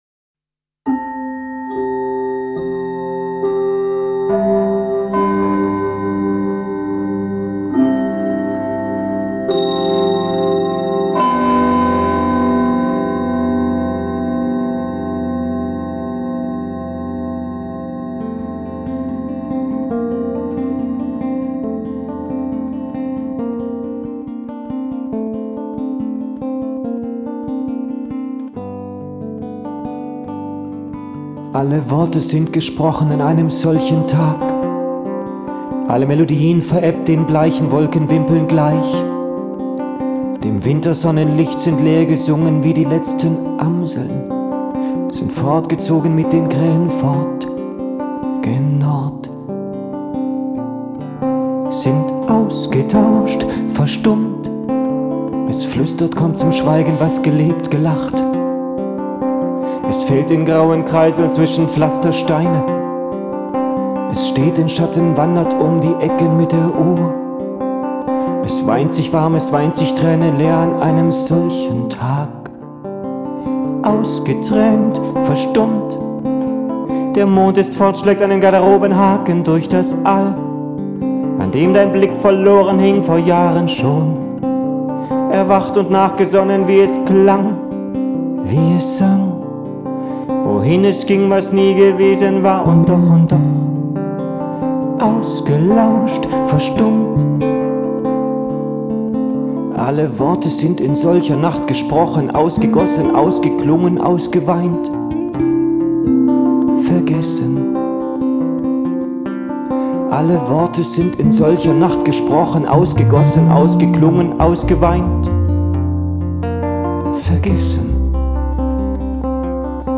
titel zu hören, brennfrisch vom premaster, sozusagen:
dieser chanson soll ihr mut machen und kraft geben und